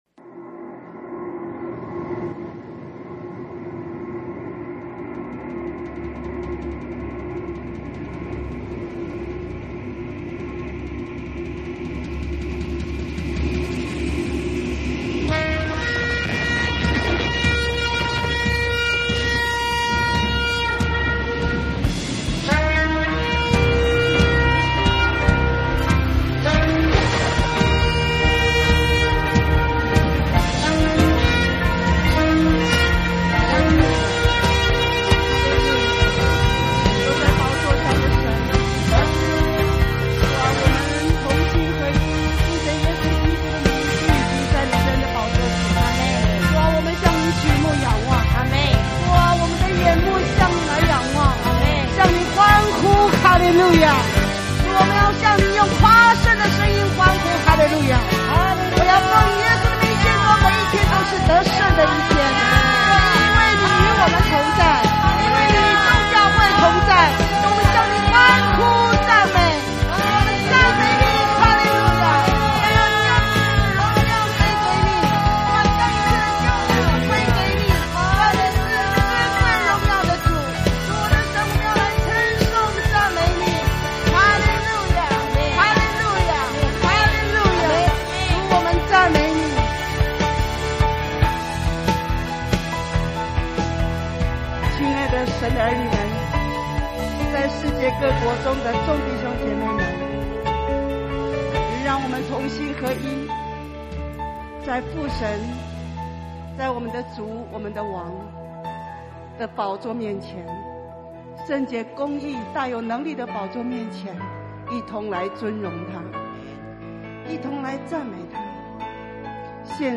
先知性敬拜禱告
【主日信息】